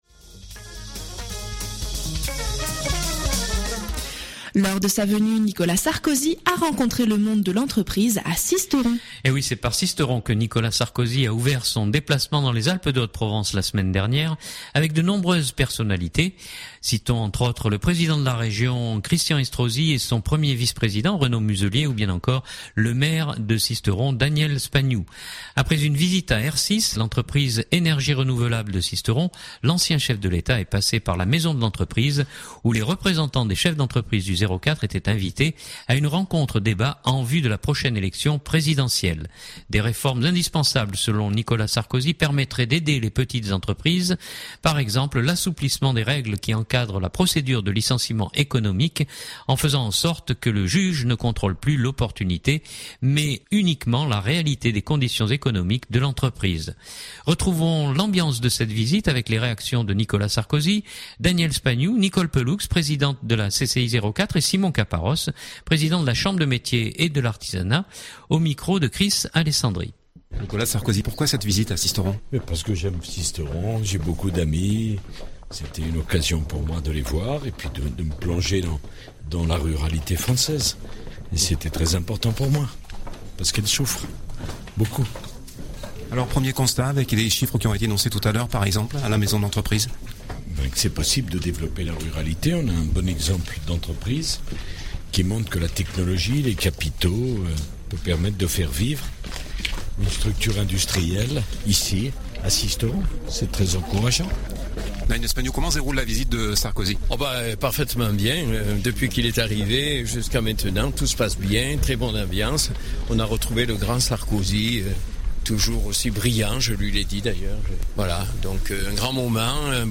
JR-2016-05-25-Sisteron-Visite_Sarkozy.mp3 (2.63 Mo)